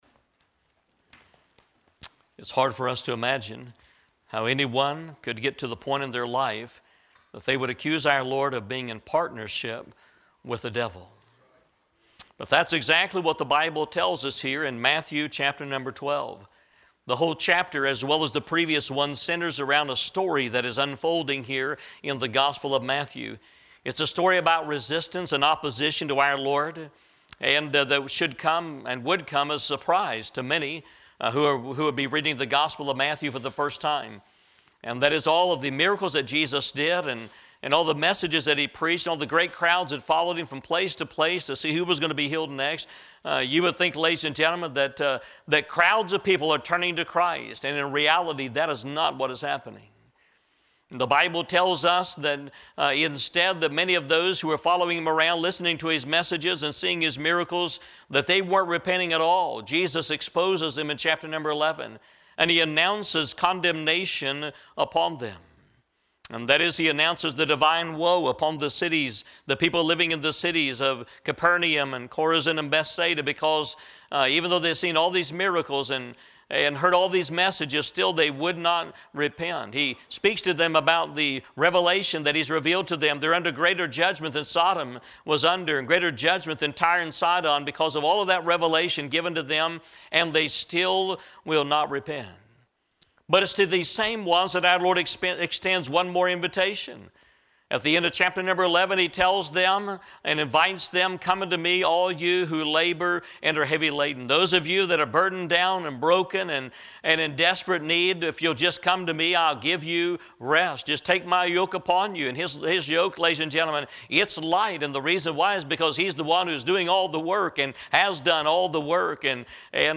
In tonight's powerful message